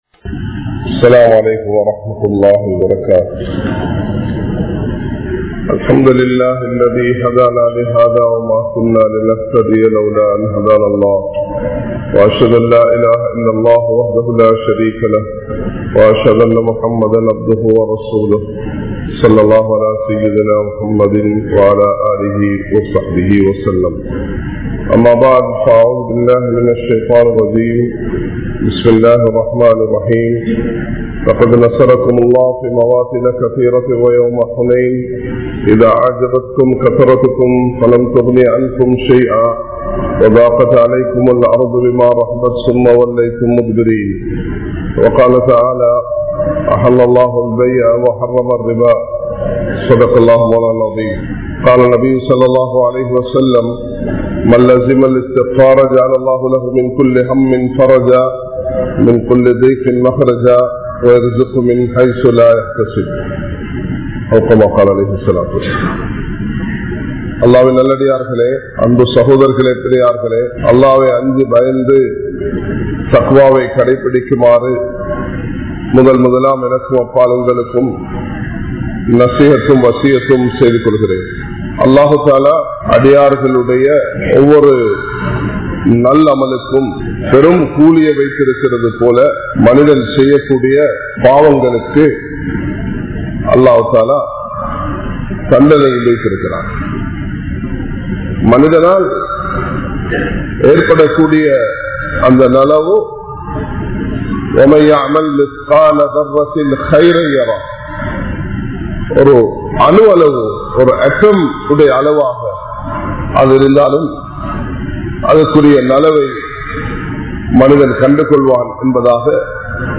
Paavaththin Mudivu Soathanaiyaahum (பாவத்தின் முடிவு சோதனையாகும்) | Audio Bayans | All Ceylon Muslim Youth Community | Addalaichenai
Dehiwela, Muhideen (Markaz) Jumua Masjith